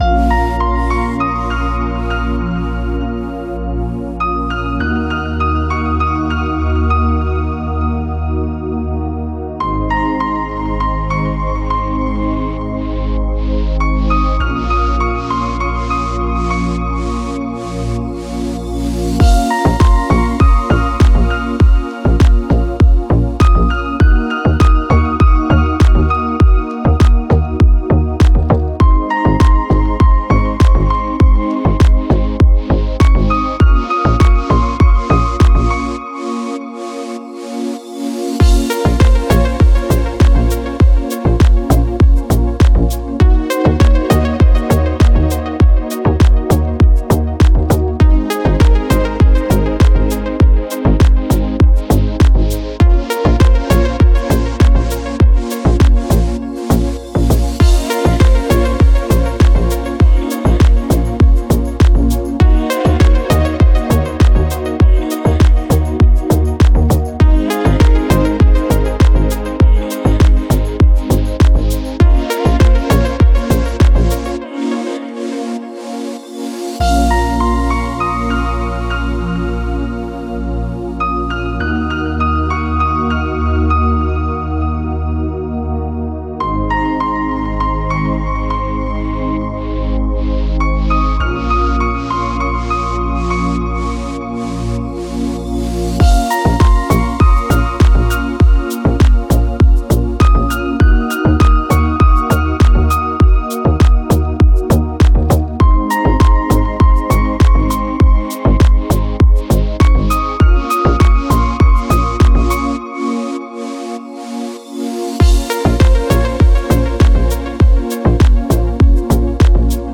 Спокойная музыка